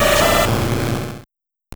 Cri d'Aligatueur dans Pokémon Or et Argent.